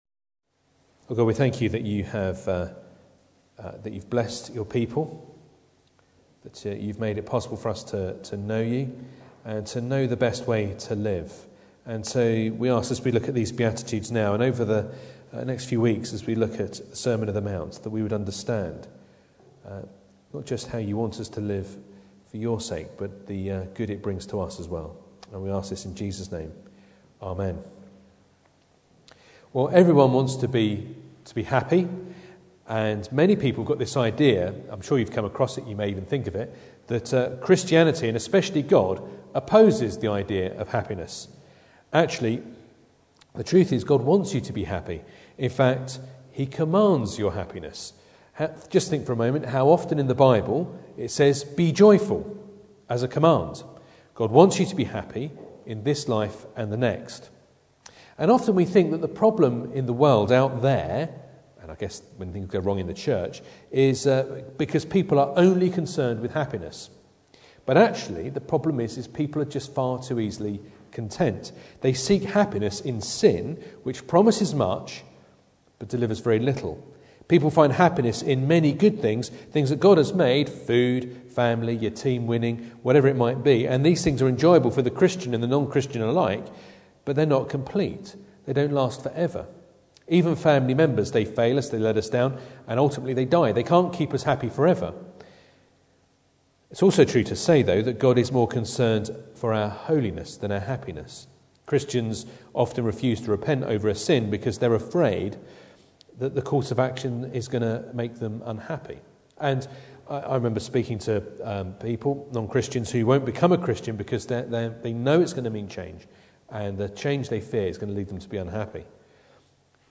Passage: Matthew 5:1-12 Service Type: Sunday Morning